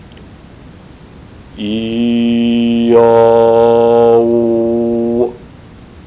Bu yazıda ayrıca ritüel sırasında zikredilen bazı sözlerin ses dosyaları vardır ve mavi gözüken bu kelimeleri tıklandığında nasıl telaffuz edildikleri sesli bir biçimde gösterilecektir.
Titreşimsel bir sesle "IAO" (iii-aaaa-ooo)  zikrediniz.